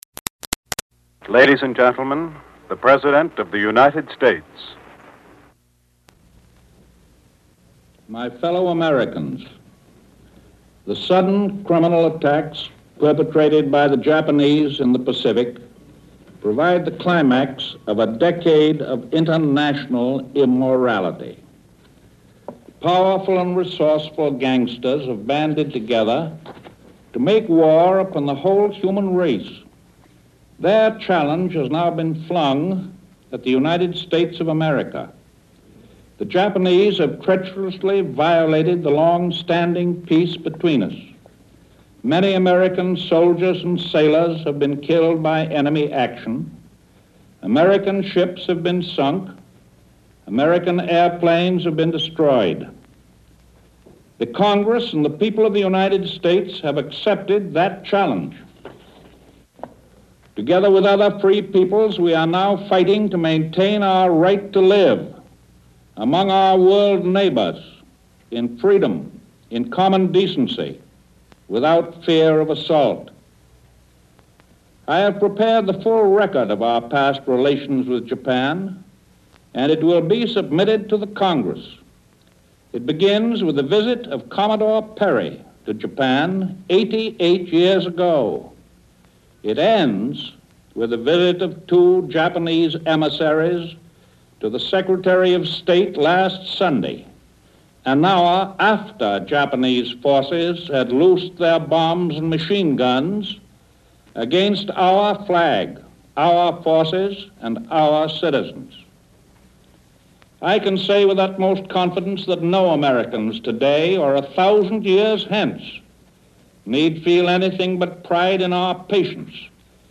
In this address just two days after the attack on Pearl Harbor, Roosevelt prepares the nation for the war ahead.